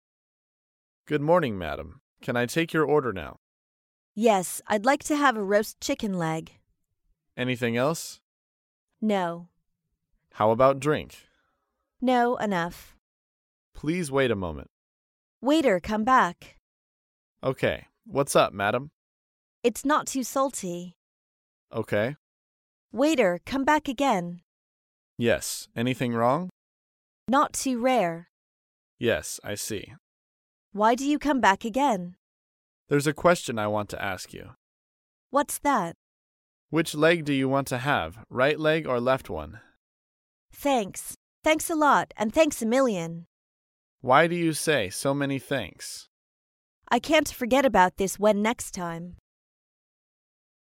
高频英语口语对话 第111期:麻烦对方致谢 听力文件下载—在线英语听力室